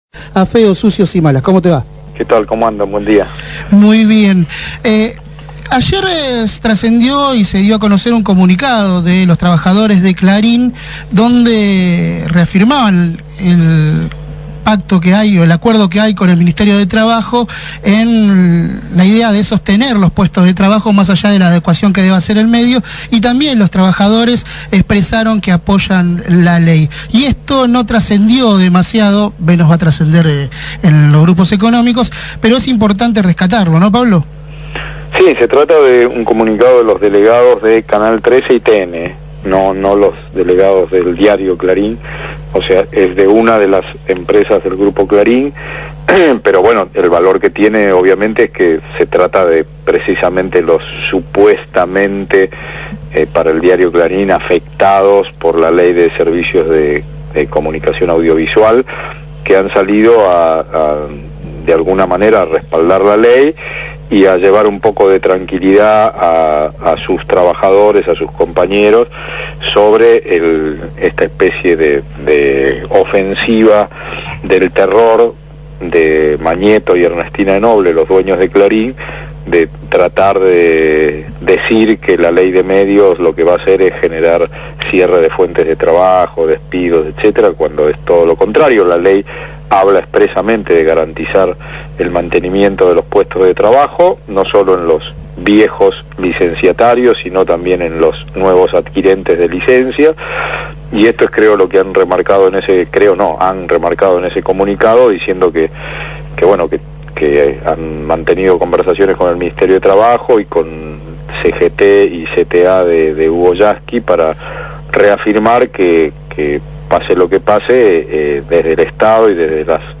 una extensa entrevista en Feos, Sucios y Malas. En la misma aportó información acerca del fallo de la Corte Suprema, las denuncias de algunos comunicadores en Washington y de la situación de los trabajadores de Canal 13 y TN.